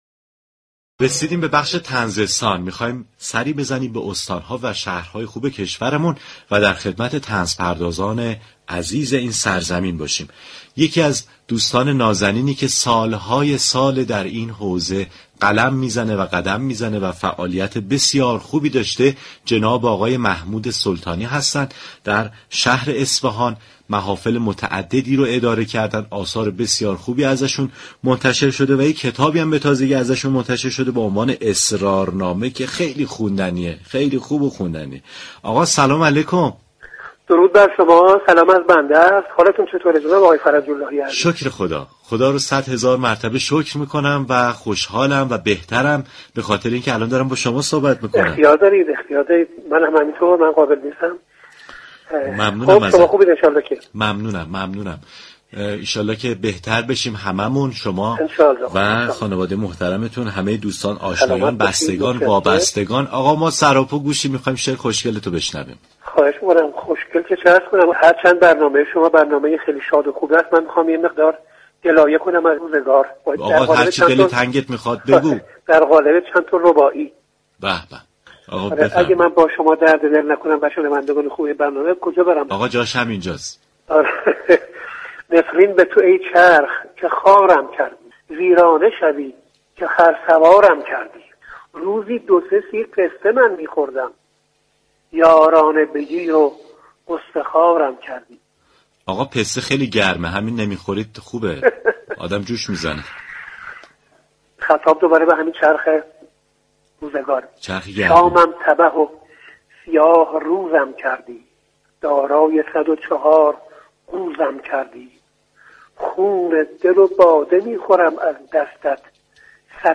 گفتگوی رادیو صبا